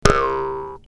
clock05.mp3